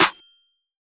MZ Snareclap [Metro #7].wav